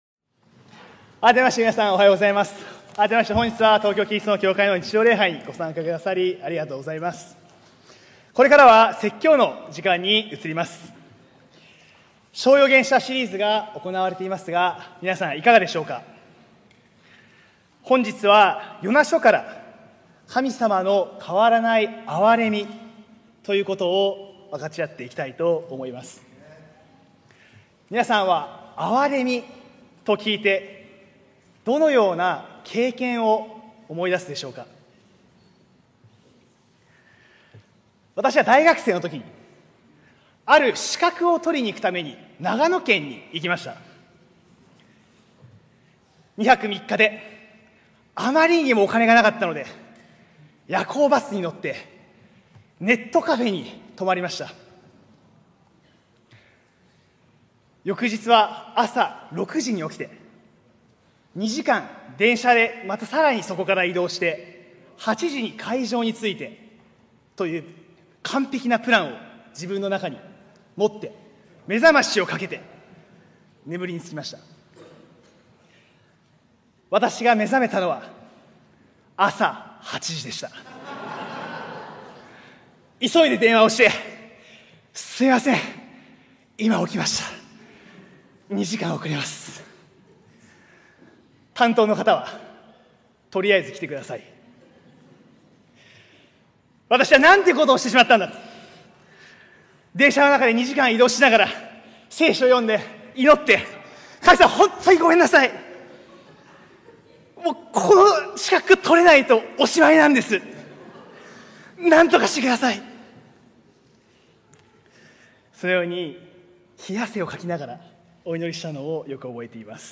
日本ジュビリー 保護者クラス(小4以上